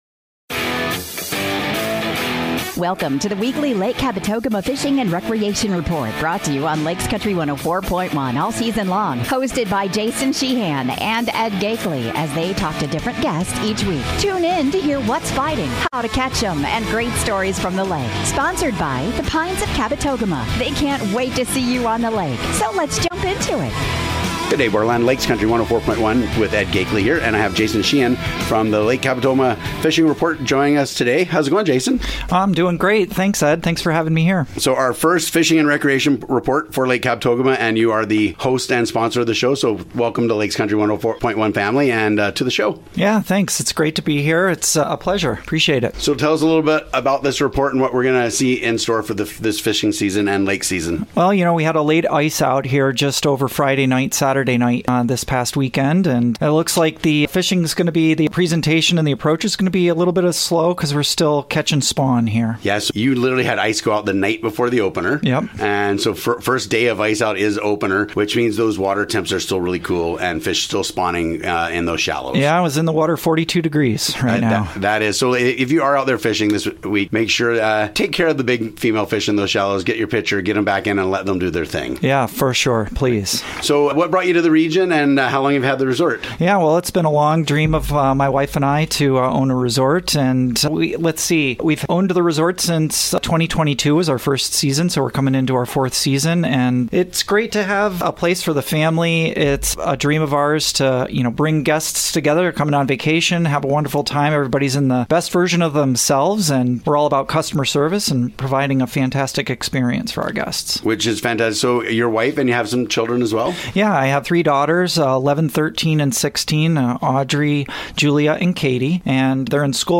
sits down with longtime guide